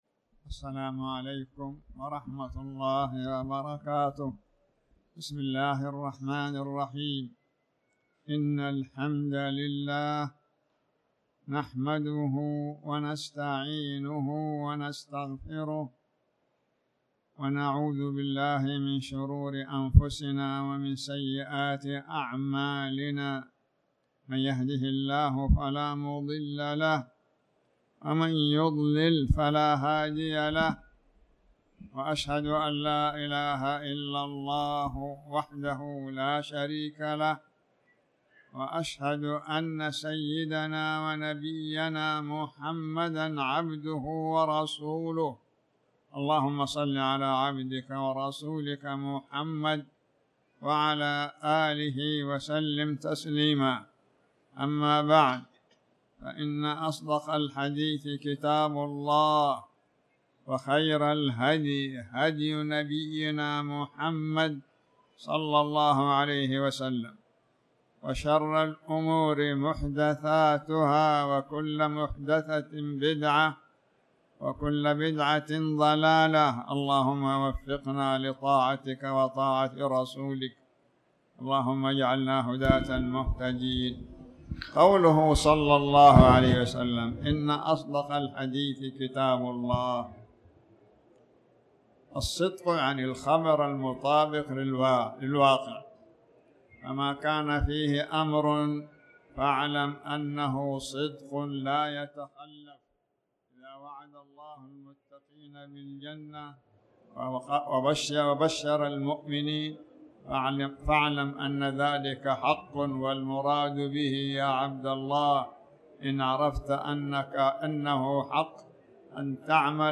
تاريخ النشر ٣٠ ربيع الثاني ١٤٤٠ هـ المكان: المسجد الحرام الشيخ